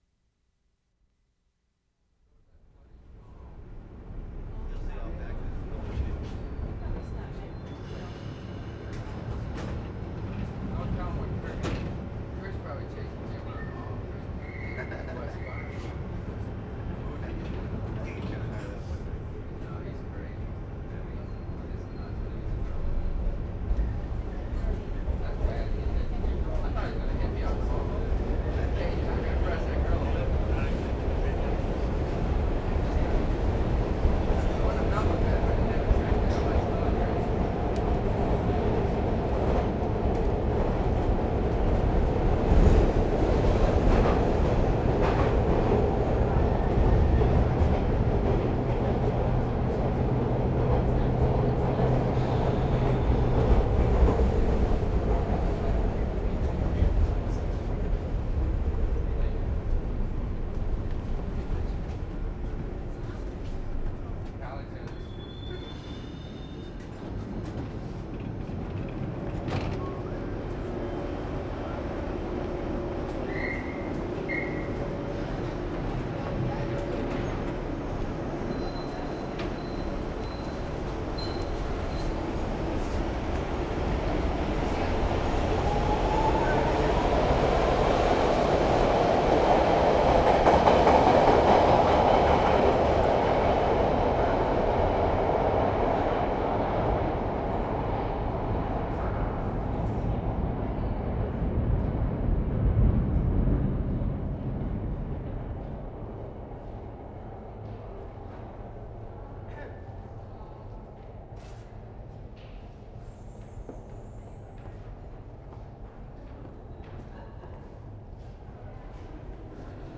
TORONTO, ONTARIO Nov. 3, 1973
COLLEGE ST. STATION 4'45"
3. Recordists leave subway train (0110"); conductor's whistle (0'10", 2'24"); standing on platform as train leaves (0'30") and second train arrives (1'15"). Short silence (1'45"-1'55"). Train arrives and leaves (2'10"-2'45"). Longer silence (3'10" to end of take). Incredible roar when trains arrive and leave. In silences between trains one can hear people's voices.